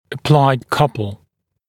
[ə’plaɪd ‘kʌpl][э’плайд ‘капл]прилагаемая пара (сил)